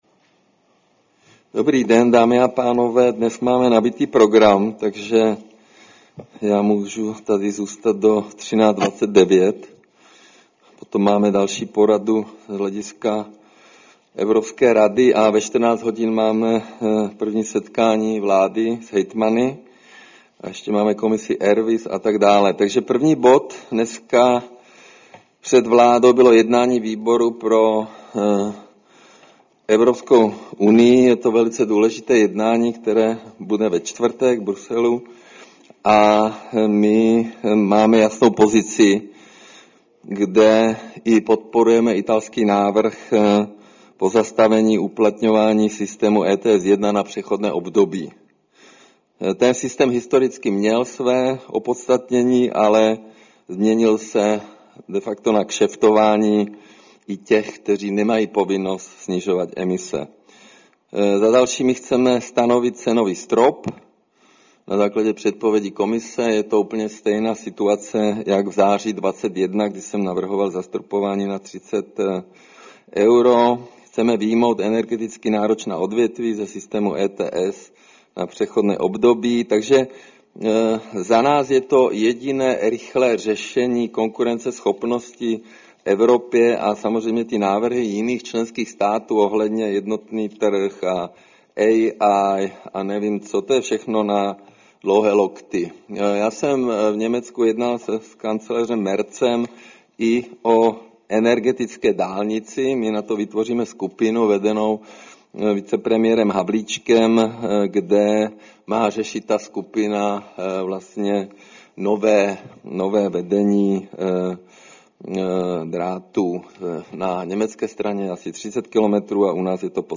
Tisková konference po jednání vlády, 16. března 2026